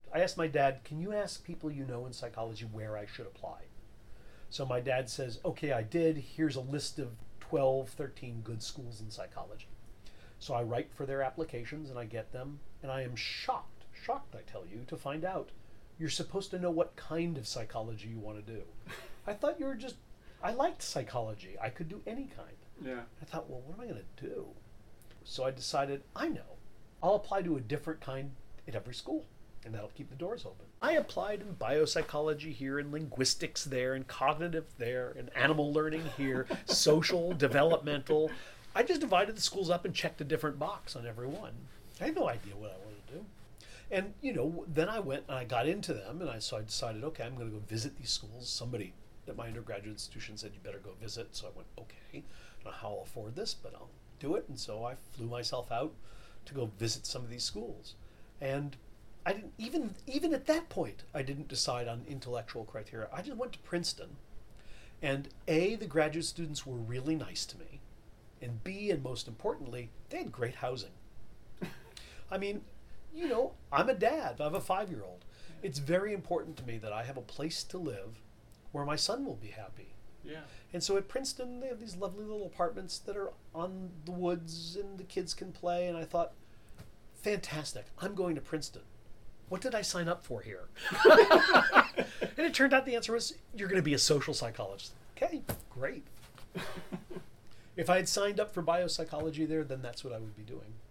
Here is Dr. Gilbert discussing how he ended up at Princeton University and in a graduate program to become a social psychologist: